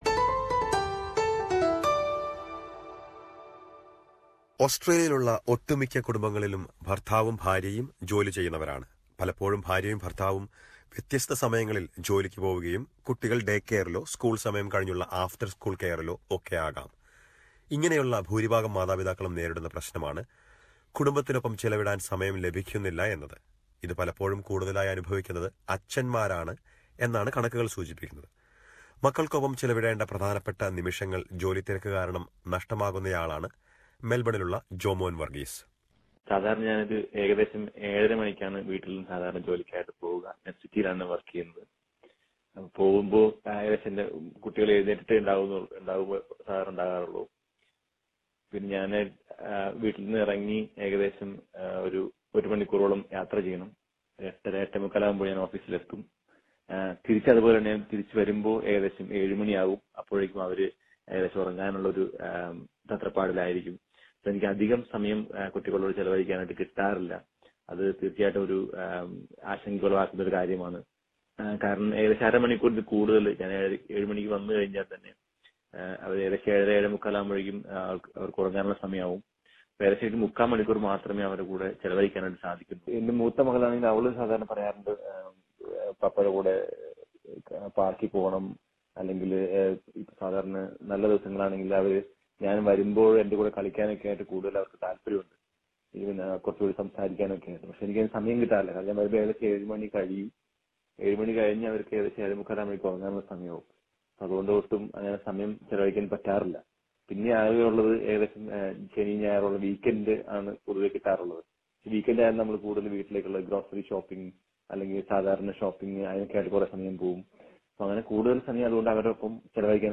ഇത് കുട്ടികളുടെ മാനസിക വളർച്ചയെ ബാധിച്ചേക്കുമെന്ന് പുതിയ പഠനം വെളിപ്പെടുത്തുന്നു. ഇത് സംബന്ധിച്ച് ചില മാതാപിതാക്കളോട് എസ് ബി എസ് മലയാളം സംസാരിച്ചിരുന്നു. ഇതേക്കുറിച്ചൊരു റിപ്പോർട്ട് കേൾക്കാം മുകളിലെ പ്ലേയറിൽ നിന്ന്...